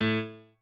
piano7_11.ogg